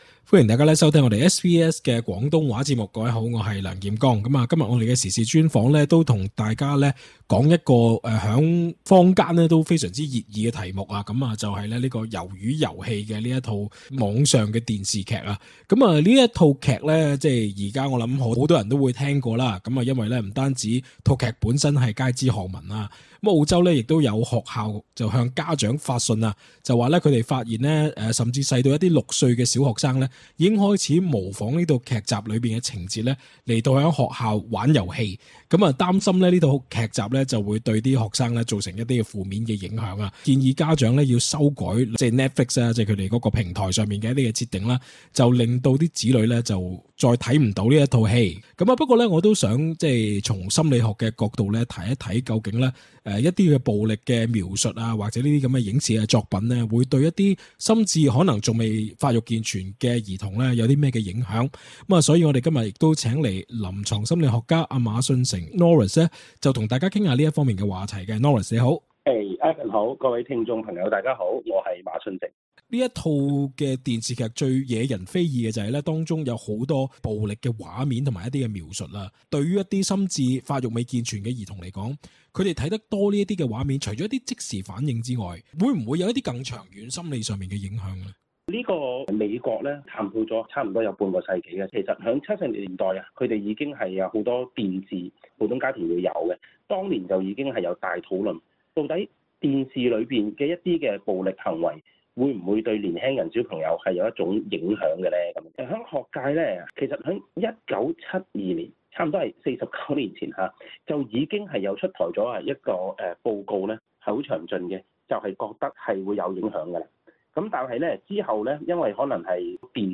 interview_squid_game_podcast.mp3